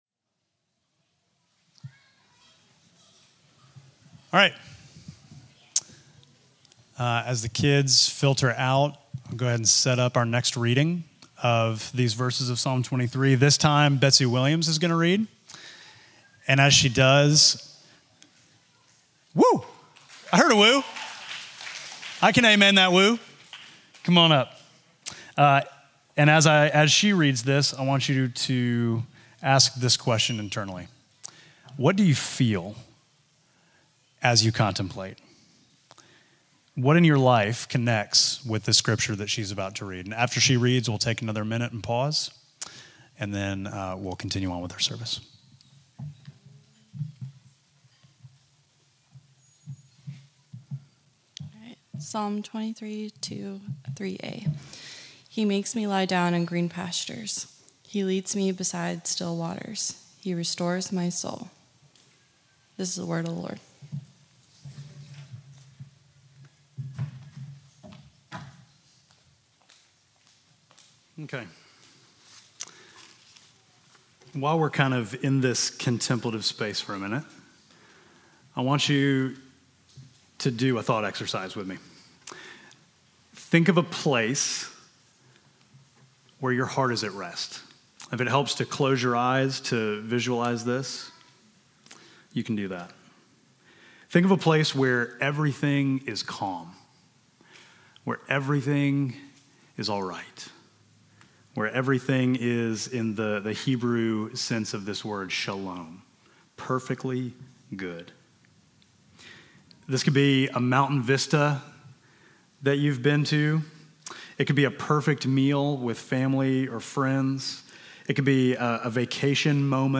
Midtown Fellowship Crieve Hall Sermons Rest and Restoration Jun 16 2024 | 00:30:25 1x 00:00 / 00:30:25 Subscribe Share Apple Podcasts Spotify Overcast RSS Feed Share Link Embed